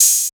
Open Hats
Metro OH3.wav